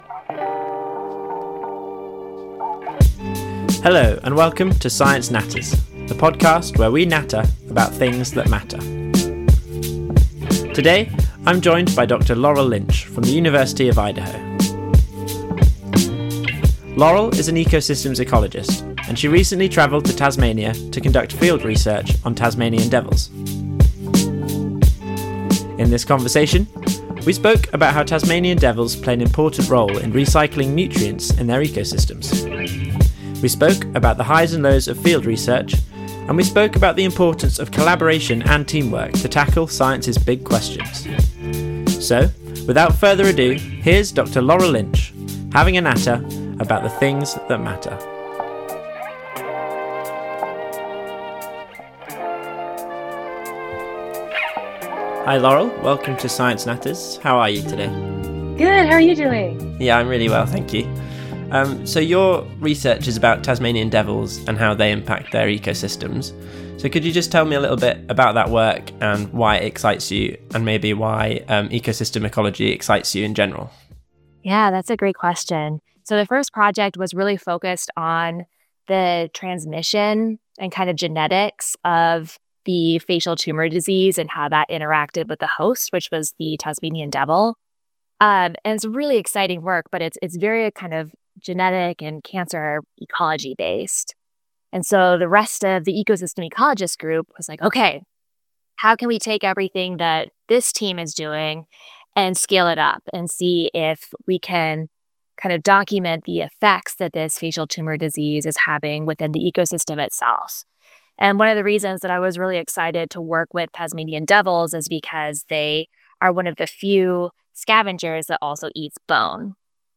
In this conversation, we speak about the important role that Tasmanian devils play in recycling nutrients in their ecosystems, the h